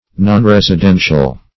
nonresidential - definition of nonresidential - synonyms, pronunciation, spelling from Free Dictionary